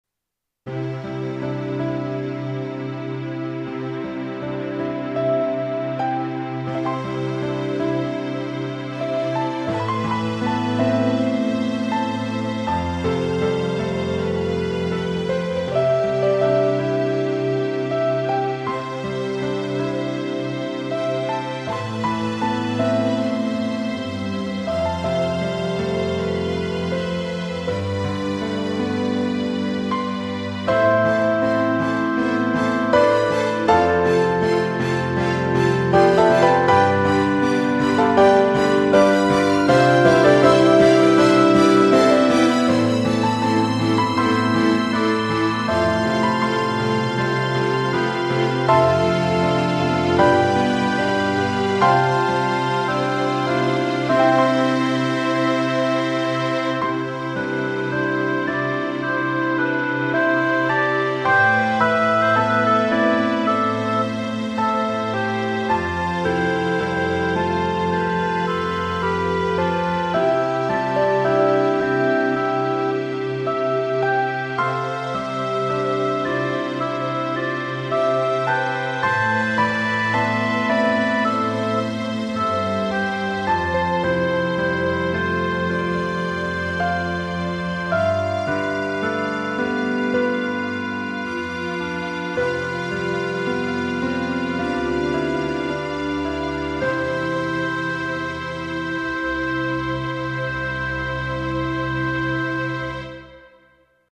YAMAHA MU90にて録音(1.59 MB)